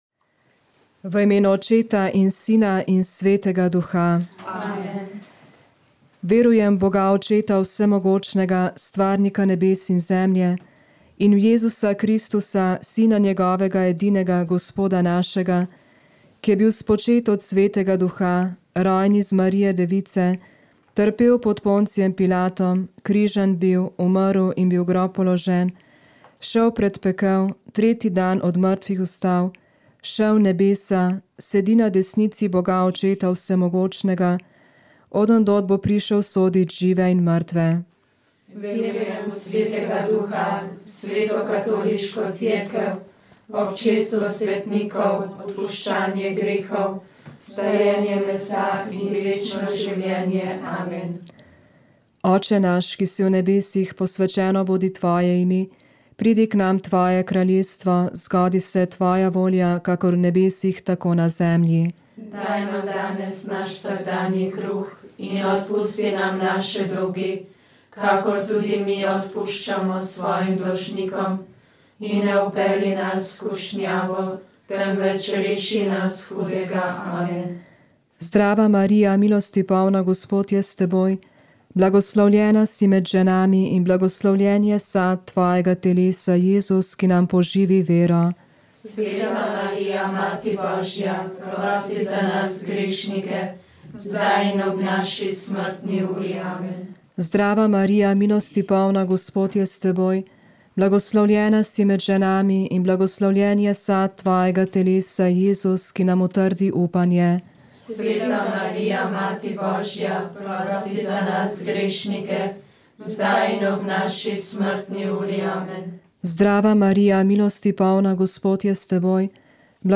Njegovo pričevanje v dveh delih je bilo posneto v letih 1978 in 1979. Dosegljivo v Arhivu Republike Slovenije.